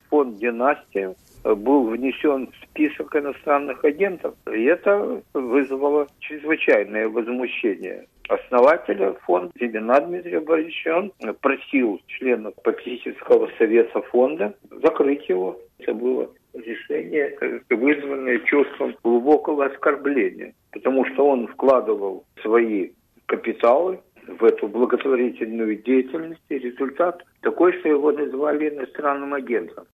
Ликвидация фонда "Династия" - это удар государства по самому себе. Такое мнение в интервью Радио Свобода выразил член совета организации, научный руководитель Высшей школы экономики Евгений Ясин — основатель фонда "Либеральная миссия".
Говорит Евгений Ясин